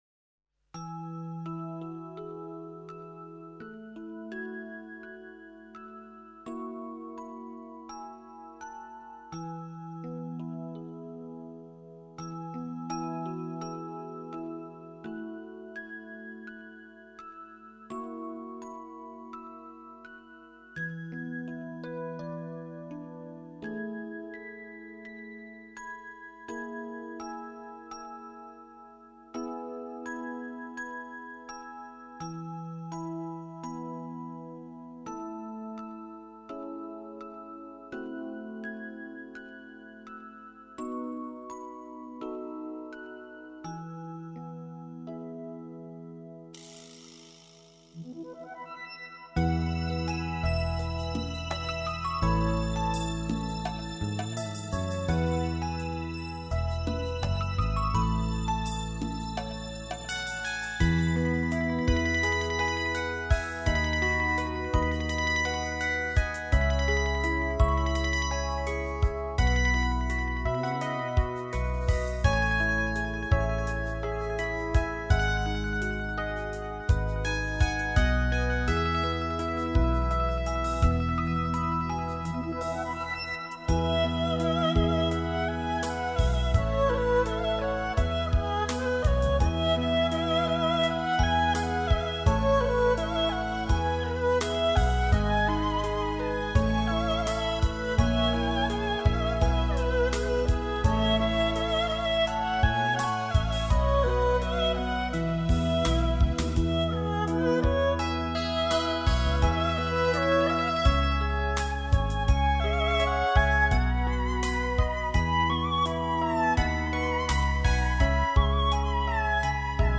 心灵NEW AGE